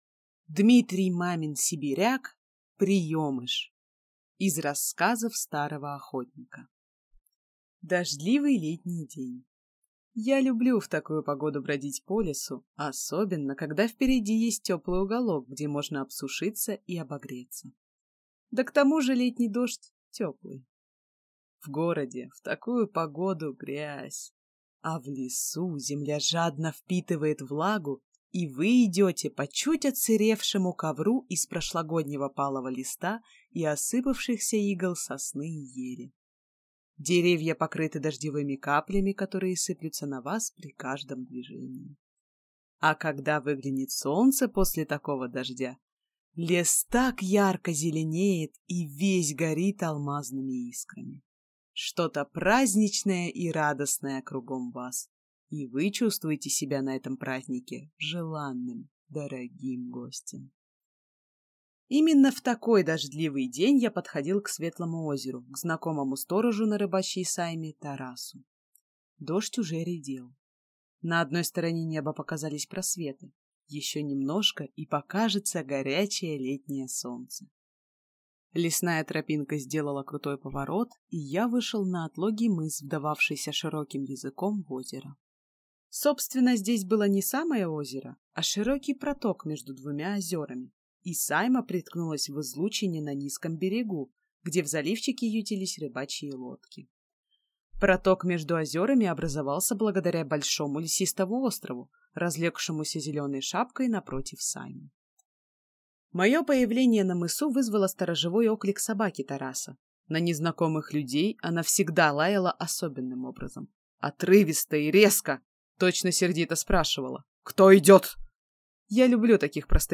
Аудиокнига Приемыш | Библиотека аудиокниг
Прослушать и бесплатно скачать фрагмент аудиокниги